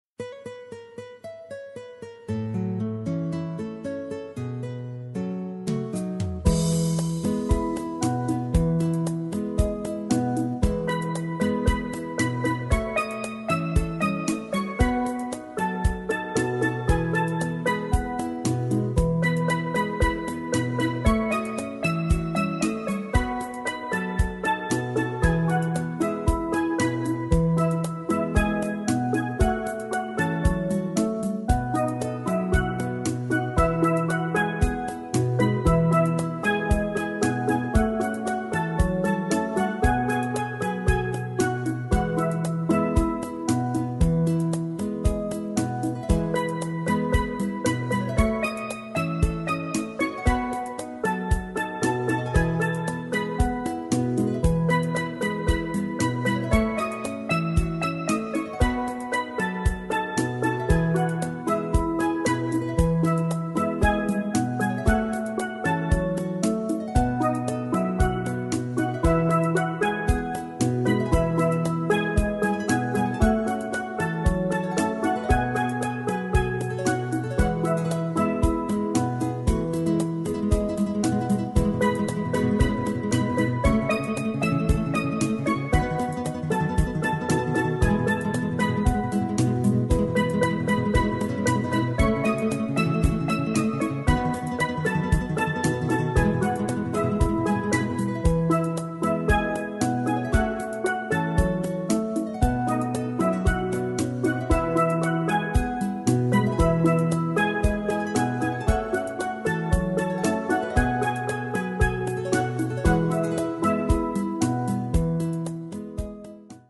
jamaica-farewell-solo-instrumental-jamaica.mp3